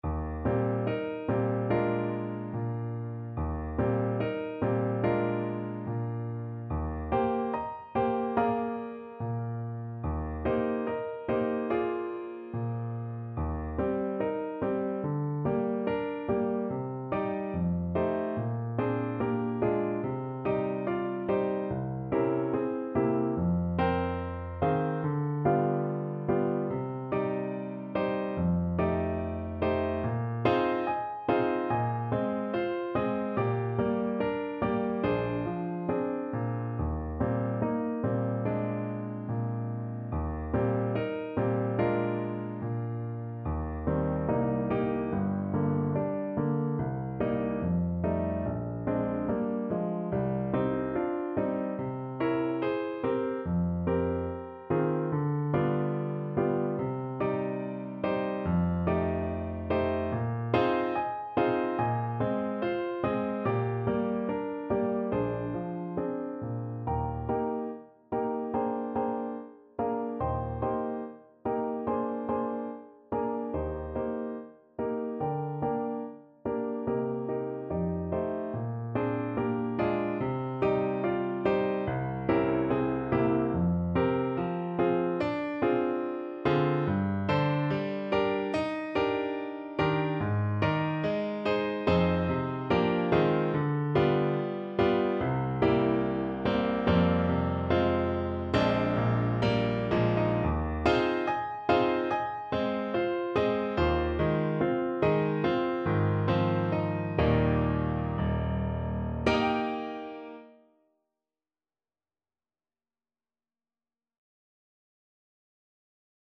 Play (or use space bar on your keyboard) Pause Music Playalong - Piano Accompaniment Playalong Band Accompaniment not yet available transpose reset tempo print settings full screen
Eb major (Sounding Pitch) F major (Clarinet in Bb) (View more Eb major Music for Clarinet )
~ = 72 In moderate time
Classical (View more Classical Clarinet Music)